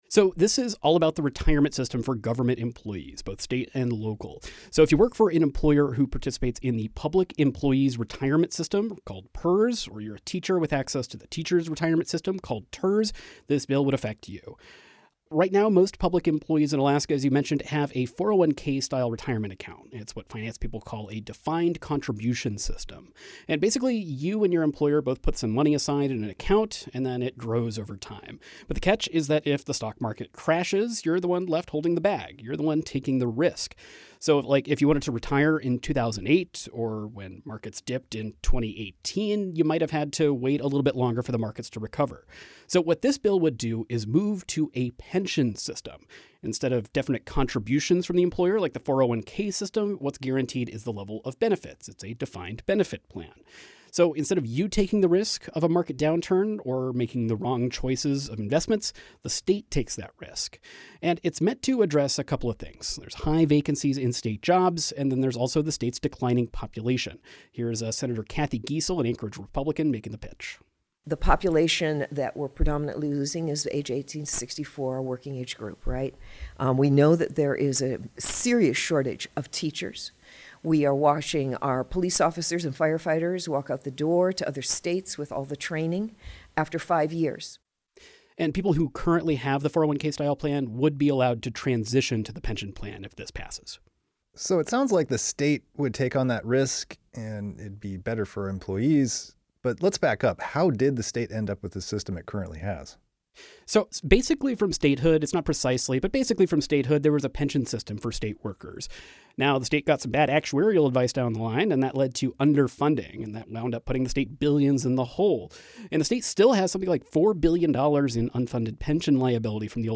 This interview has been lightly edited for length and clarity.